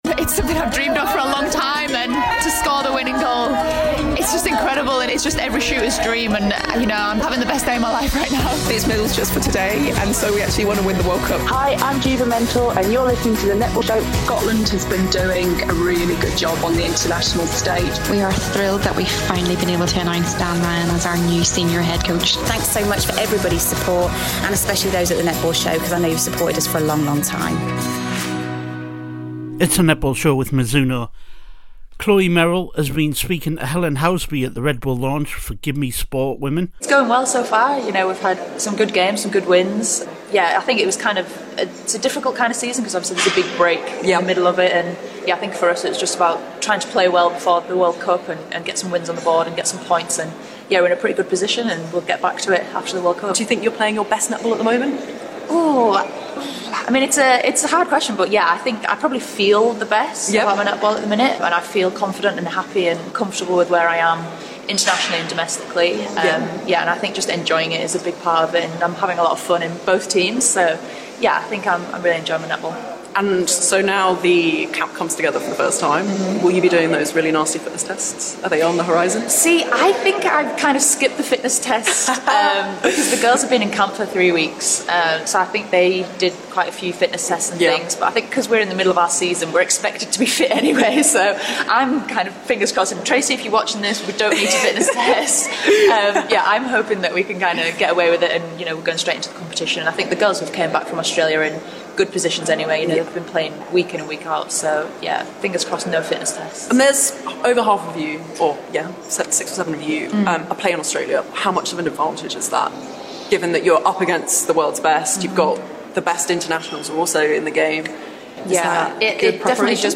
at the Red Bull launch